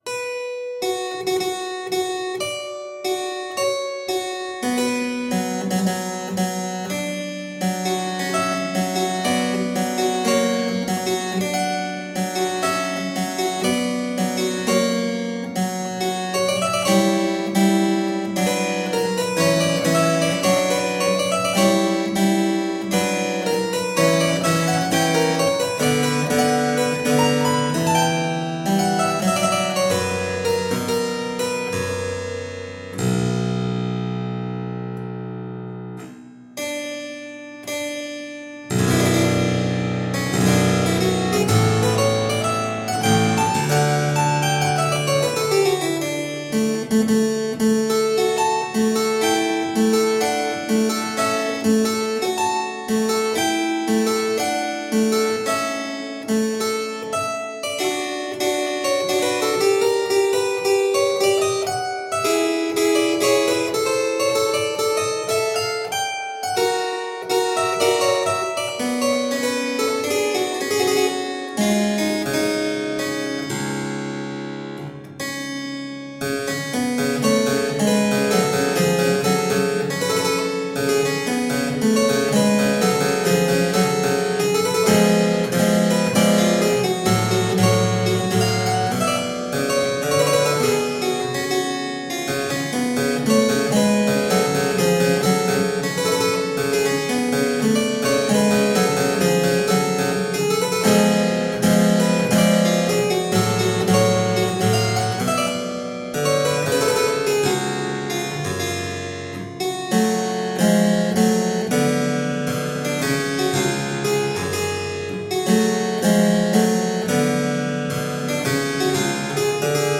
Delicious harpsichord rarities.
Classical, Classical Period, Instrumental, Harpsichord